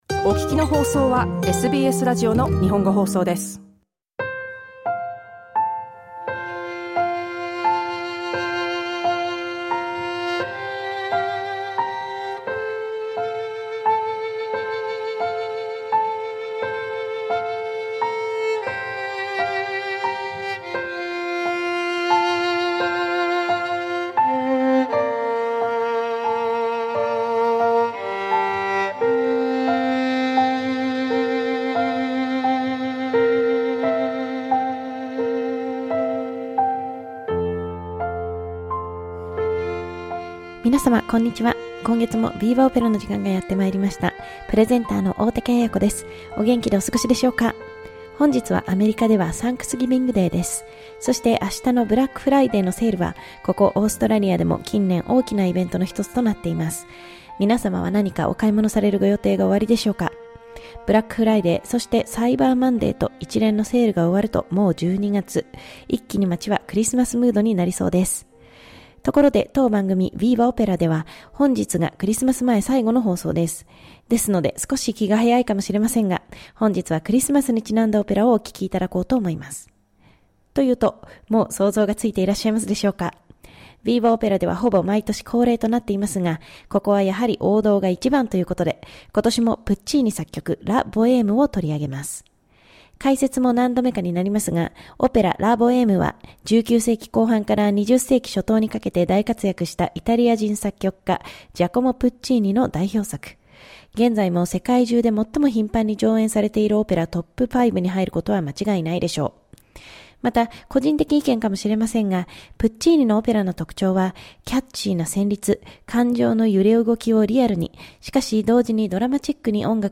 分かりやすい説明とともにお届けします。